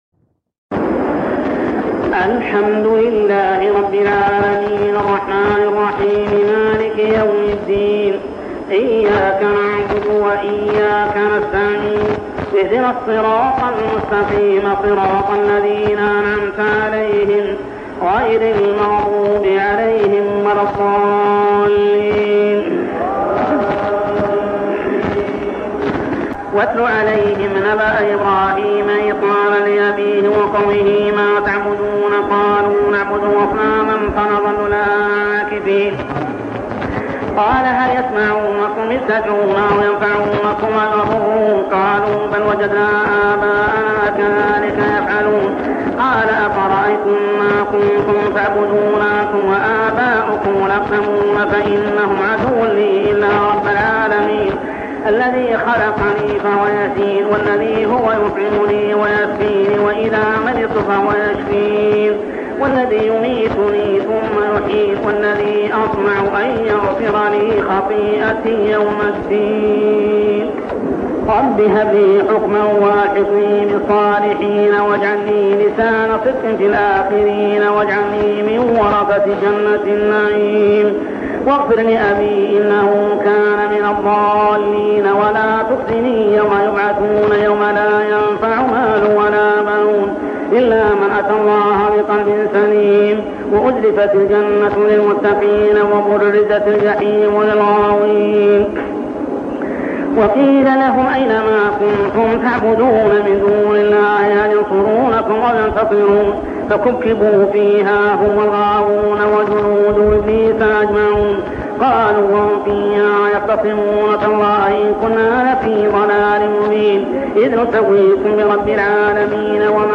جزء من صلاة التراويح عام 1402هـ ( بقية الليلة مفقودة ) سورة الشعراء 69-104 | Part of Tarawih prayer Surah Ash-Shu'ara > تراويح الحرم المكي عام 1402 🕋 > التراويح - تلاوات الحرمين